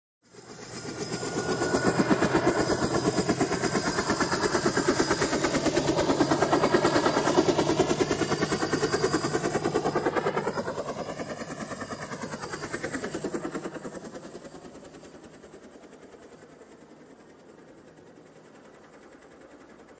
Helicopter
Tags: cartoon tv